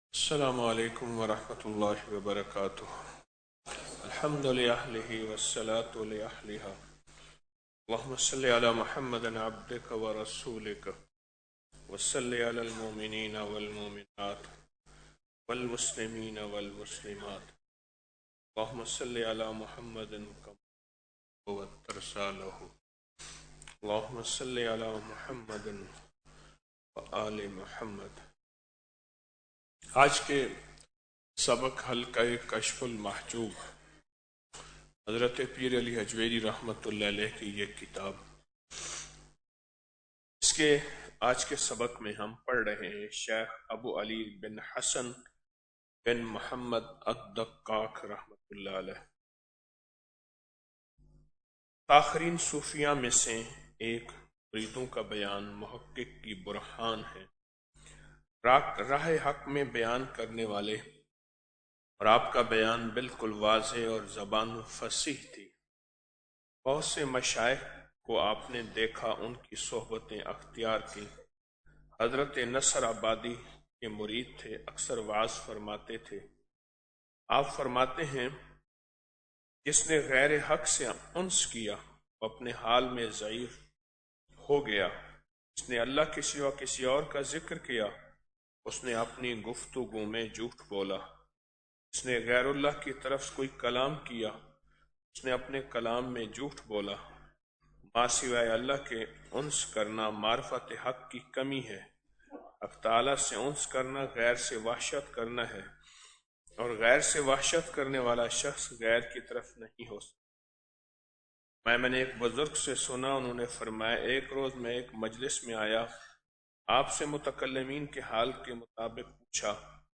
Audio Speech - 08 Ramadan After Salat Ul Fajar - 09 March 2025